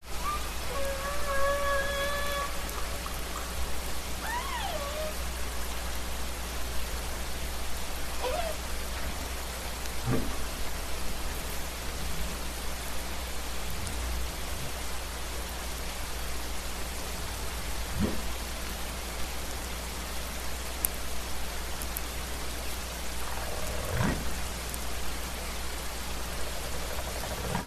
دانلود آهنگ دلفین 1 از افکت صوتی انسان و موجودات زنده
دانلود صدای دلفین 1 از ساعد نیوز با لینک مستقیم و کیفیت بالا
جلوه های صوتی